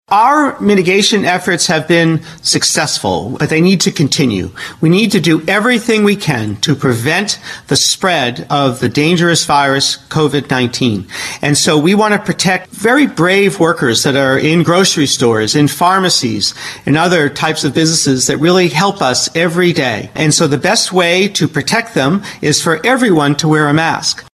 Dr. Rachel Levine, PA Secretary of Health, talked about why the Wolf Administration is now requiring workers and most customers of life-sustaining businesses which remain open to wear a mask.